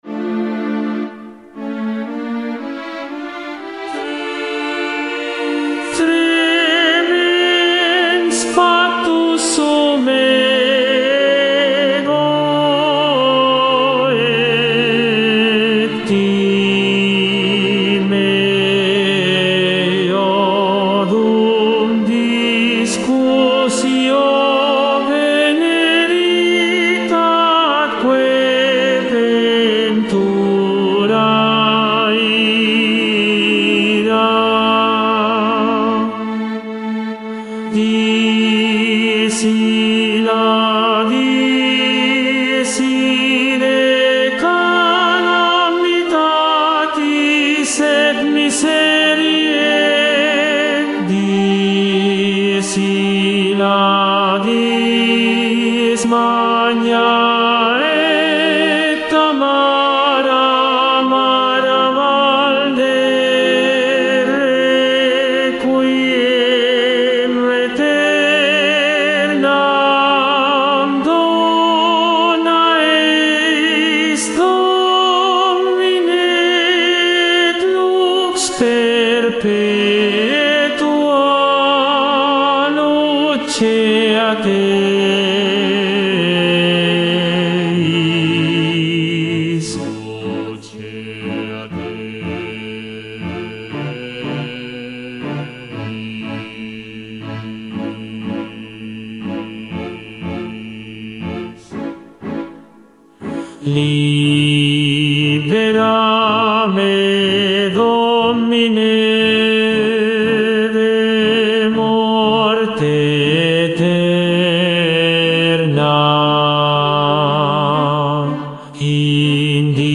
Tenor II